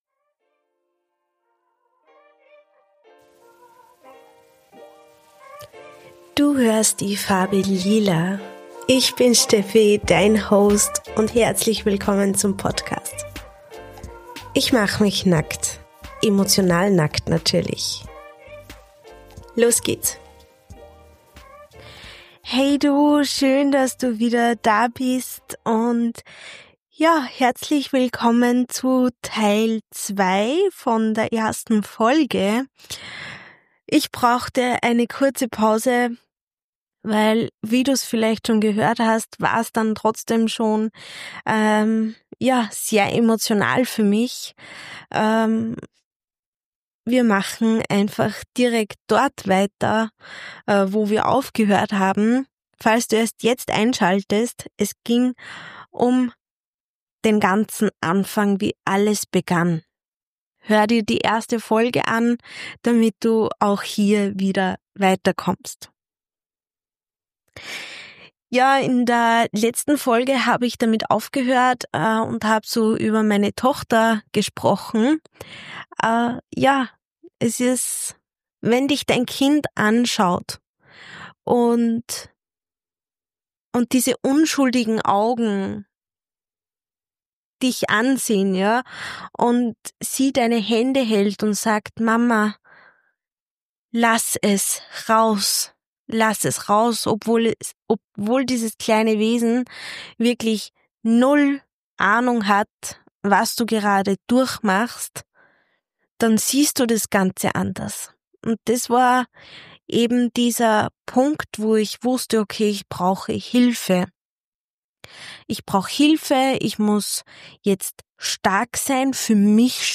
Diese Folge ist roh.